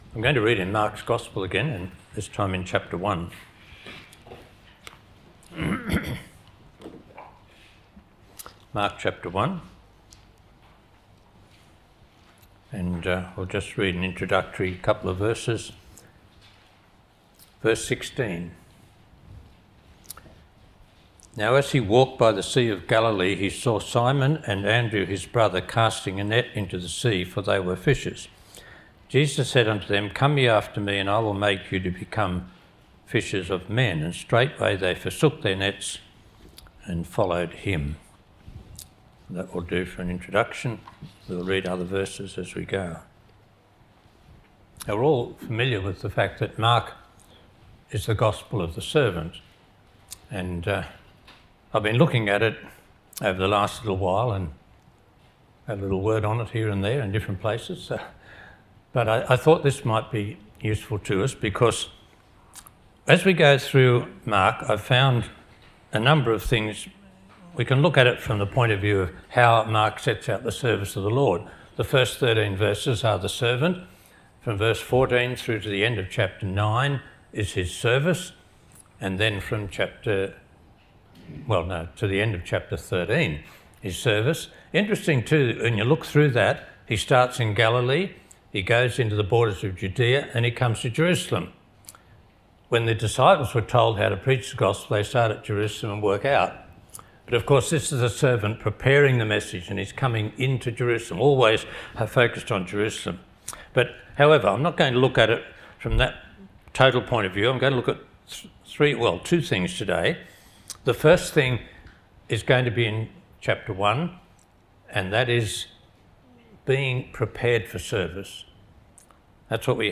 Scripture readings: Mark ch1:16-18, 19-20, 29-31, 40-45; ch4:36-40; ch6:45-47 Location: Cooroy Gospel Hall (Cooroy, QLD, Australia)
Service